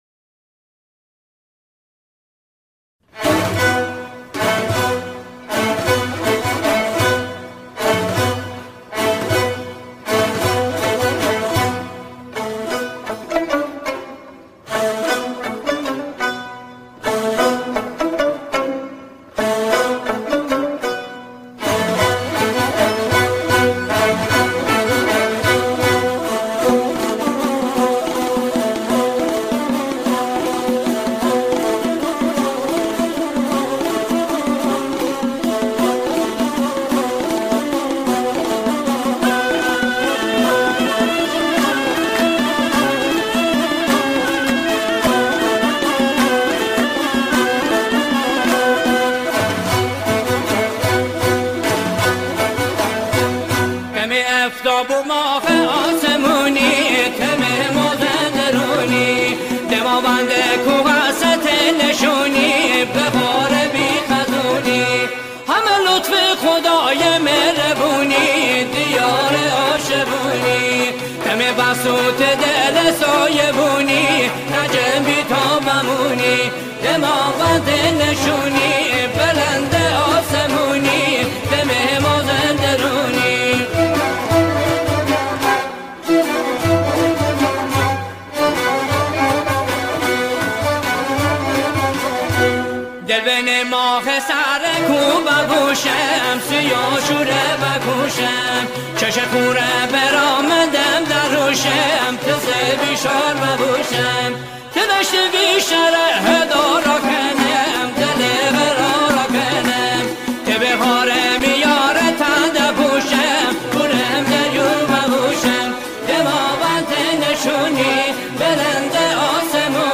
سرودهای شهرها و استانها
گروهی از همخوانان